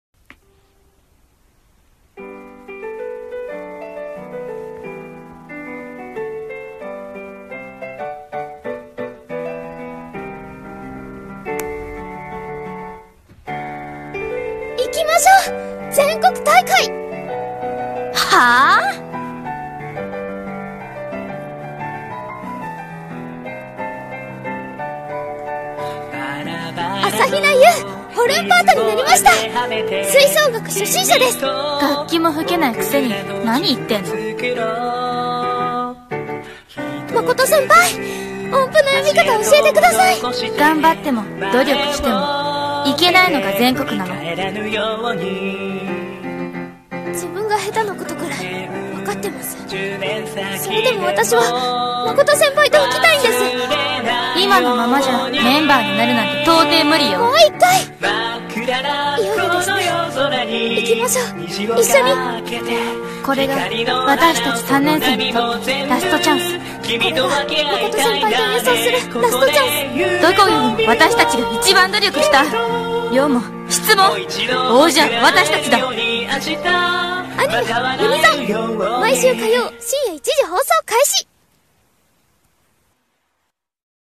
【台本】アニメ予告風声劇 ユニゾン